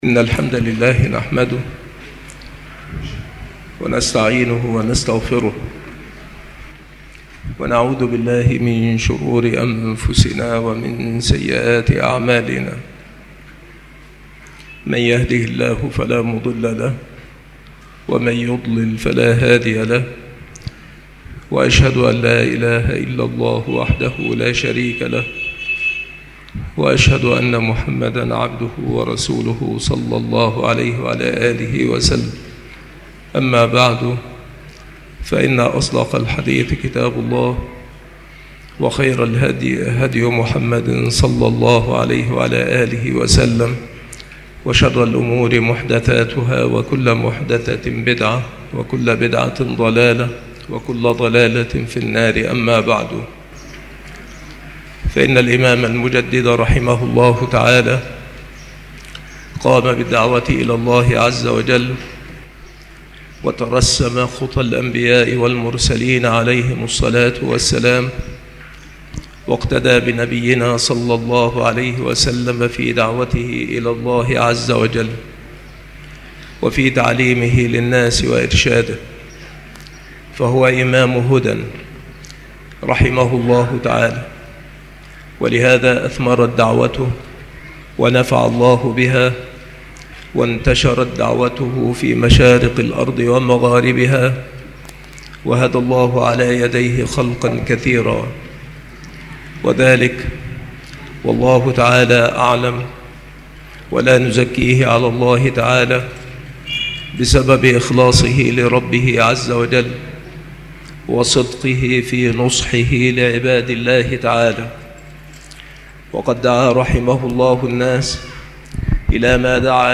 مكان إلقاء هذه المحاضرة بالمسجد الشرقي بسبك الأحد - أشمون - محافظة المنوفية - مصر عناصر المحاضرة